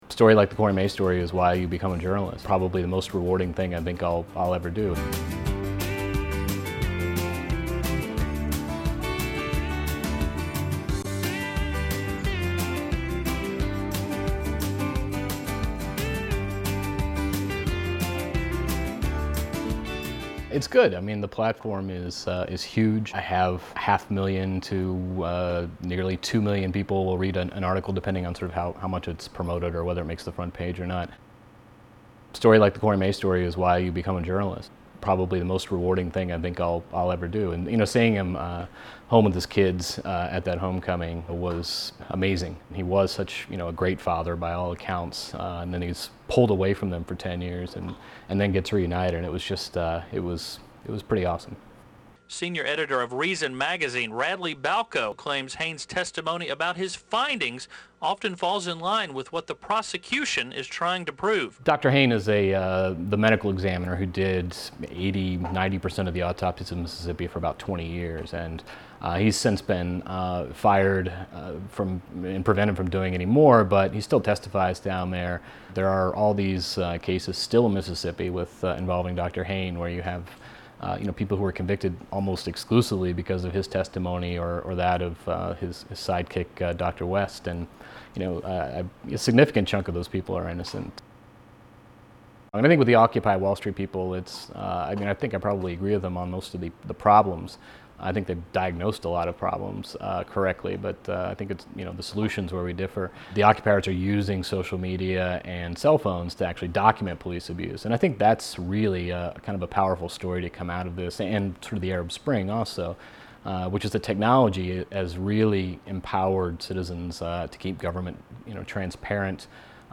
In a wide-raning conversation